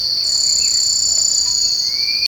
Carpinterito Cuello Canela (Picumnus temminckii)
Nombre en inglés: Ochre-collared Piculet
Localidad o área protegida: Garruchos
Condición: Silvestre
Carpinterito-cuello-canela.mp3